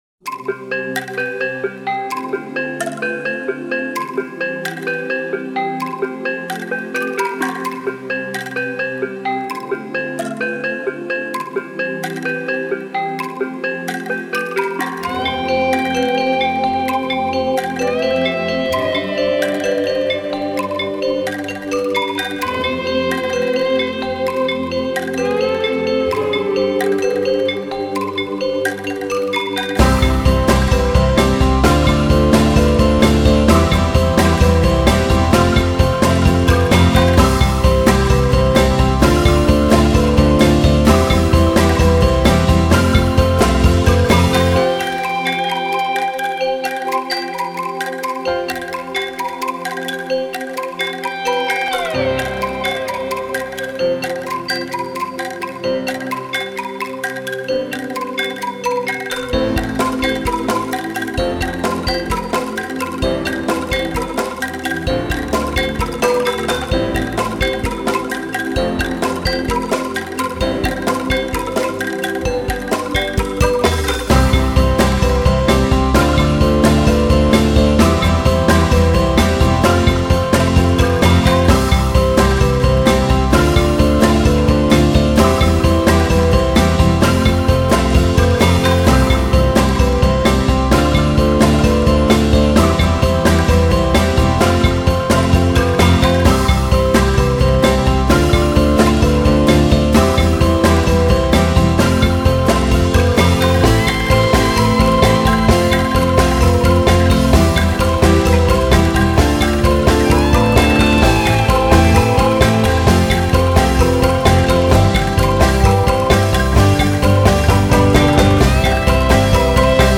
kolintang